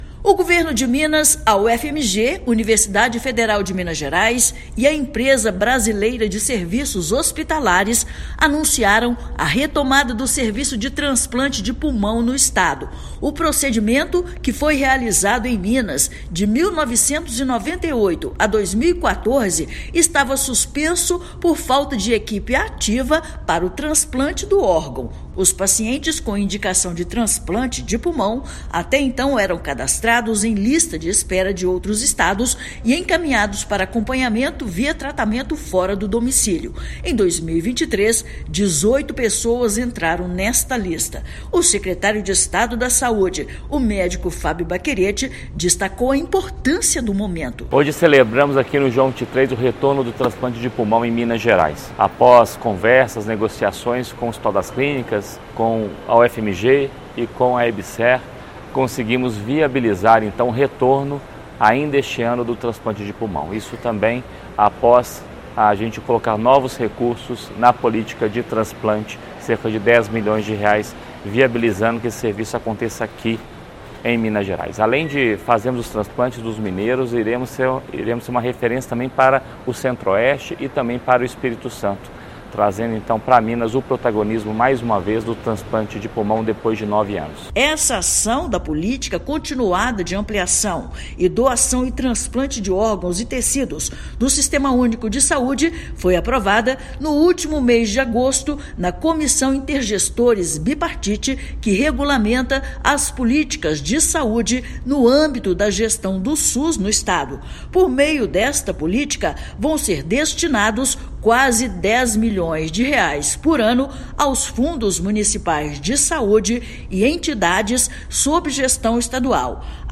Retomada do procedimento, suspenso no estado desde 2014, foi divulgada nesta quarta-feira (27/9), Dia Nacional de Doação de Órgãos; BH recebe ação de conscientização para aumentar número de doadores. Ouça matéria de rádio.